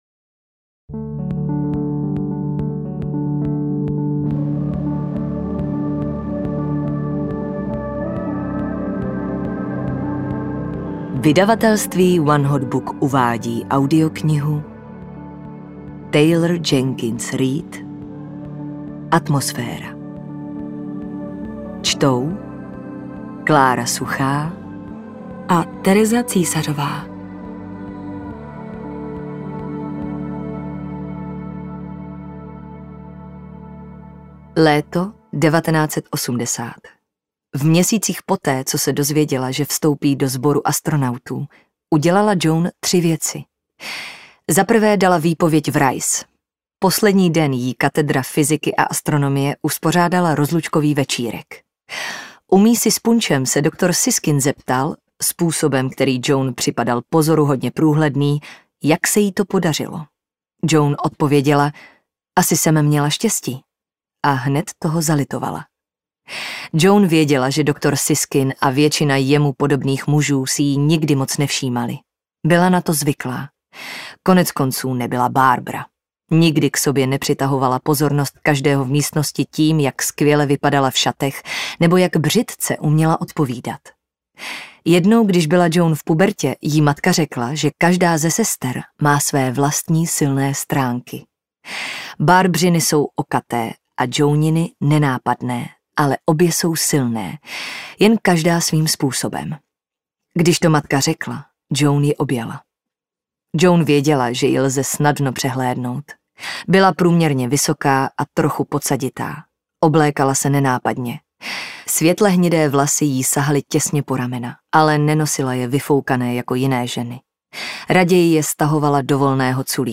Atmosféra audiokniha
Ukázka z knihy